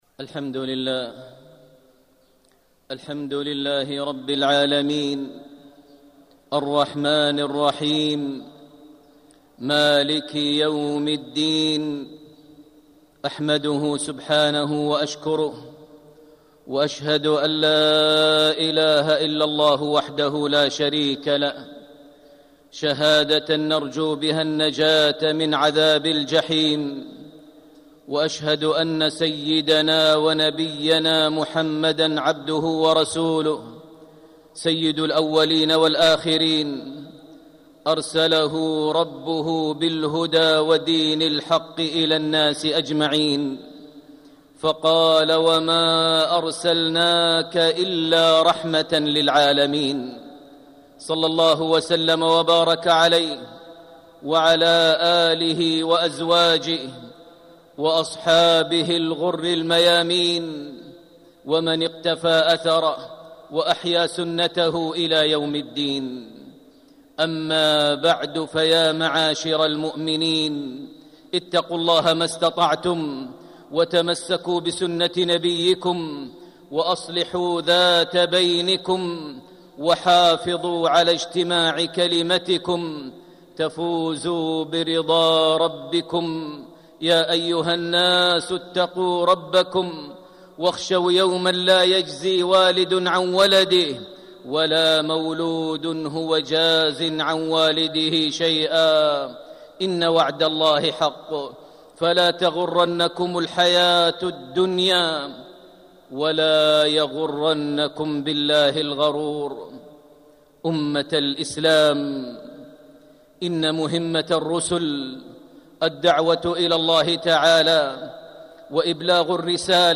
فكيف لم يحبه ﷺ أصحابه رضي الله عنهم، وكيف نحن لم نتبعه ولم نسلك دربه. التصنيف: خطب الجمعة